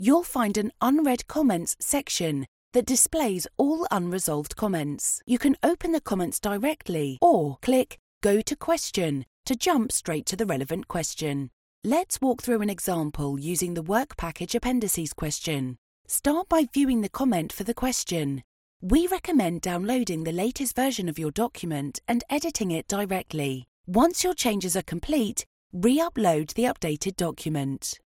E-learning
Her tone is warm and textured with a hint of gravitas. She has a clear, confident and relatable delivery. Her accent is neutral British allowing her to voice in received pronunciation or a more cool, urban style, depending on her audience. Her vocal range starts at a millennial 18 years and extends to a more mature delivery of 45 years.
Bespoke built recording studio
WarmConversationalCoolEpicConfidentClearUrbanNeutral